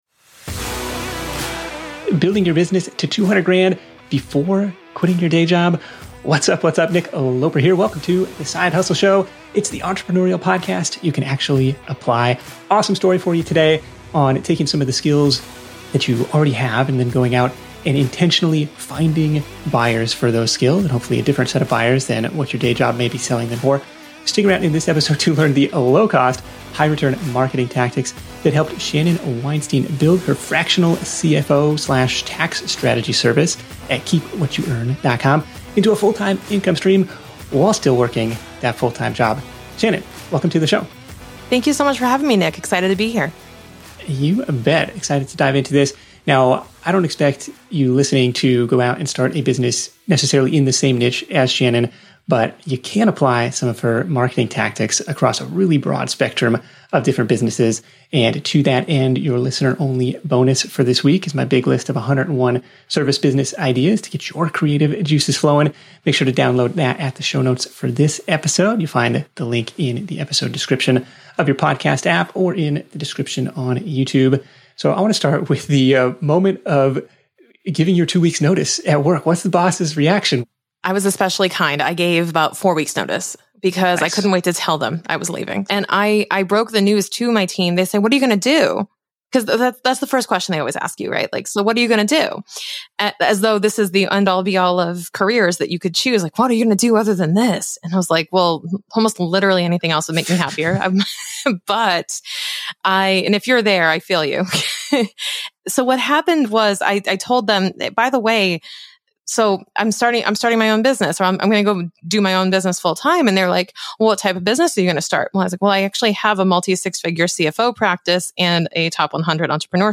Tune in to the Side Hustle Show interview to hear: the low-cost, high-return marketing tactics